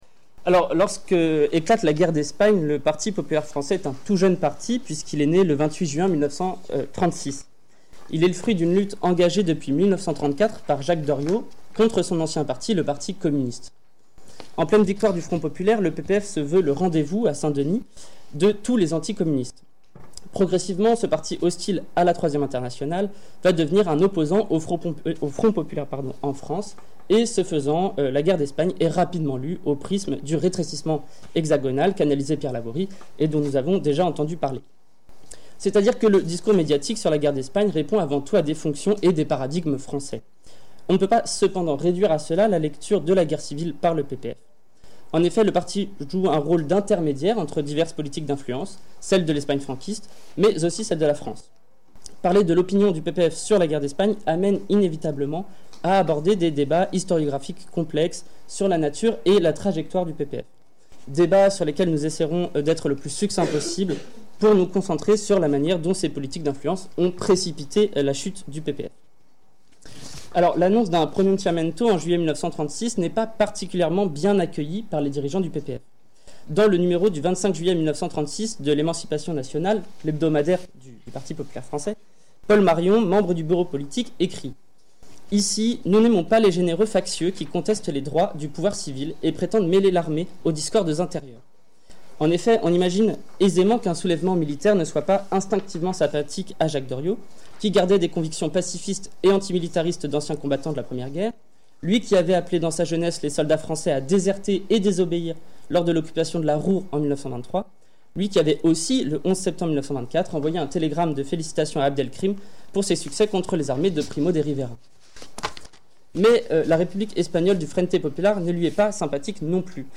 Cette communication étudie le rapport que le Parti populaire français entretient avec l’Espagne nationale de 1936 à 1939 au croisement des politiques d’influence de l’Espagne franquiste et de la France. En effet, le PPF loin d’être immédiatement favorable au soulèvement nationaliste s’en rapproche peu à peu jusqu’à obtenir des subsides du gouvernement franquiste.